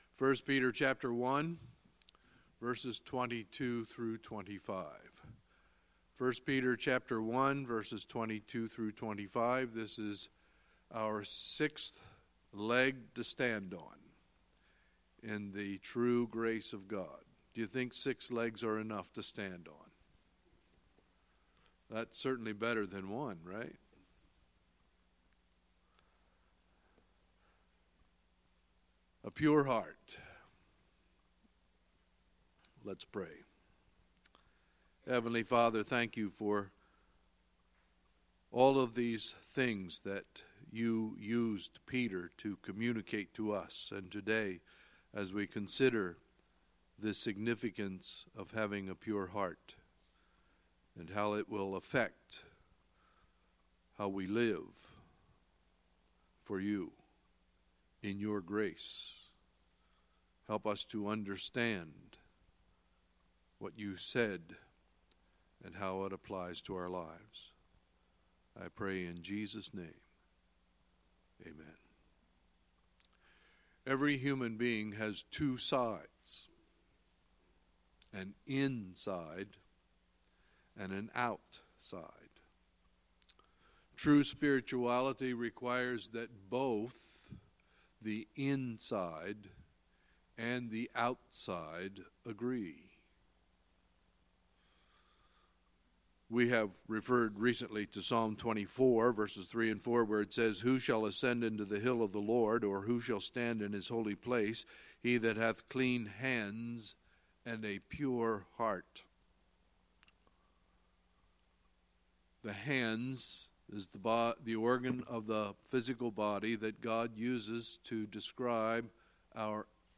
Sunday, March 18, 2018 – Sunday Morning Service